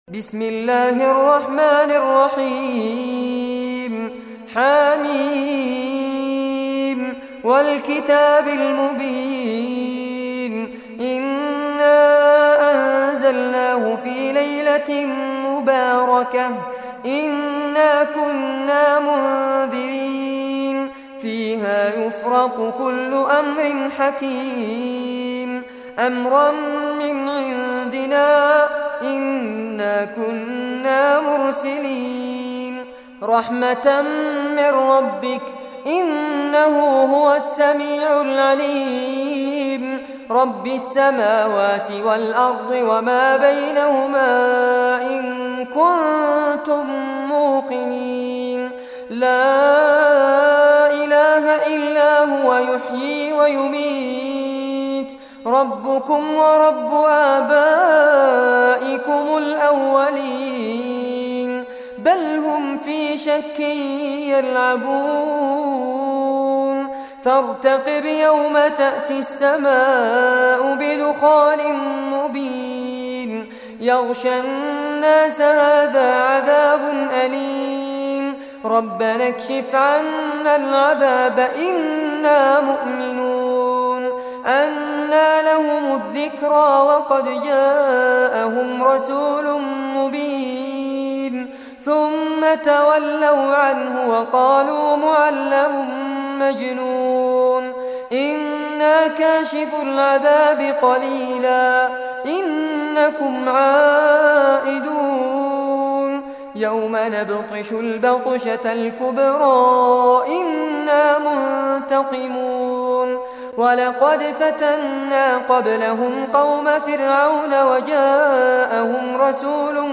Surah Ad-Dukhan Recitation by Fares Abbad
Surah Ad-Dukhan, listen or play online mp3 tilawat / recitation in Arabic in the beautiful voice of Sheikh Fares Abbad.
44-surah-dukhan.mp3